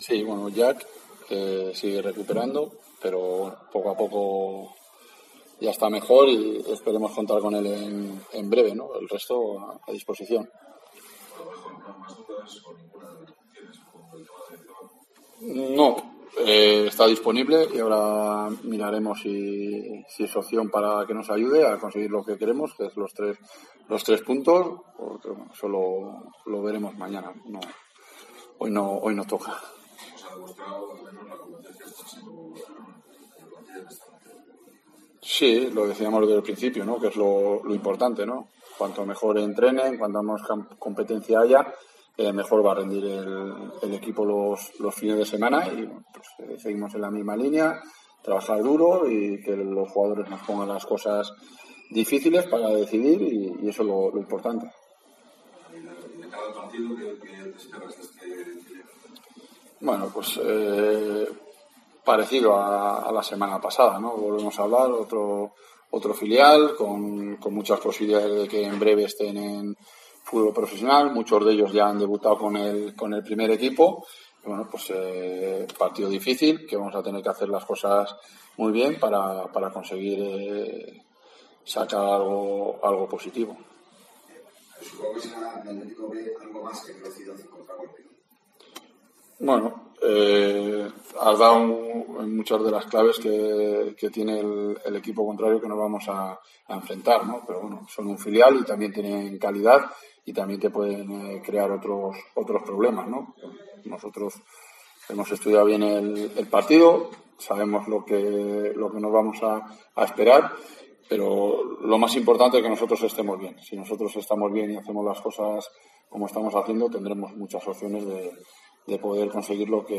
Escucha aquí las palabras del entrenador de la Deportiva Ponferradina, Jon Pérez Bolo